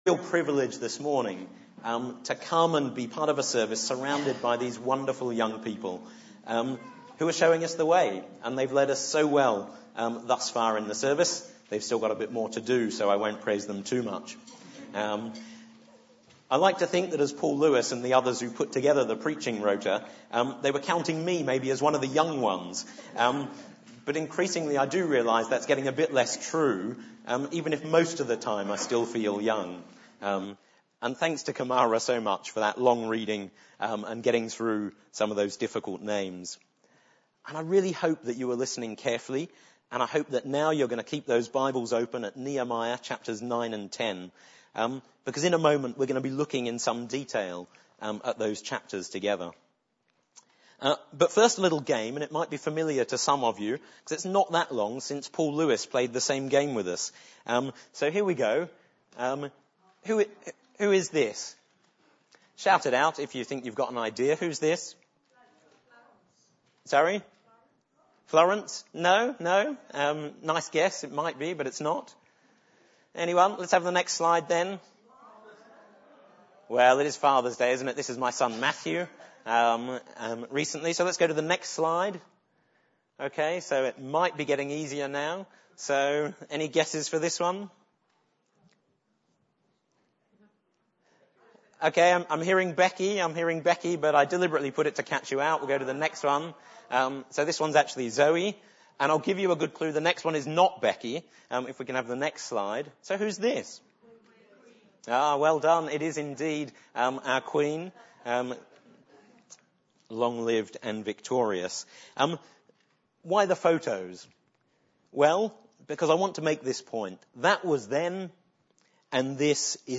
The sermon had a song and a YouTube clip in the middle. These have been removed for copyright reasons.